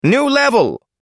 new_level.mp3